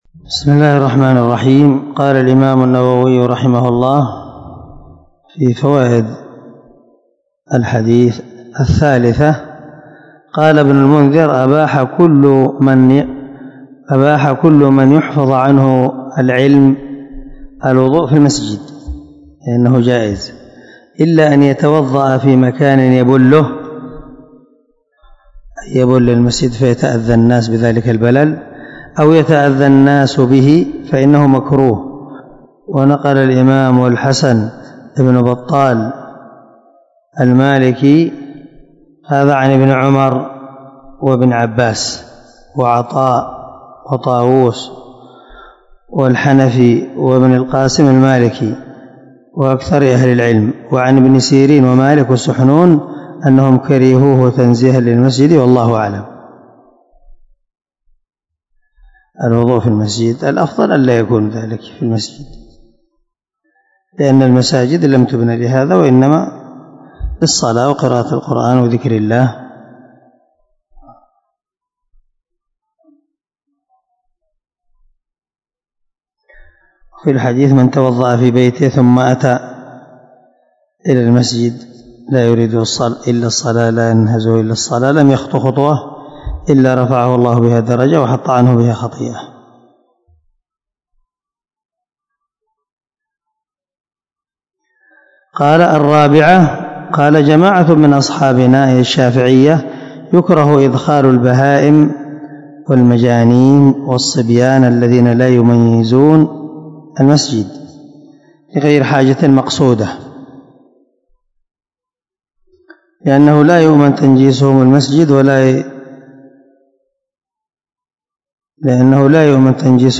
214الدرس 42 من شرح كتاب الطهارة حديث رقم ( 286 – 287 ) من صحيح مسلم
دار الحديث- المَحاوِلة- الصبيحة.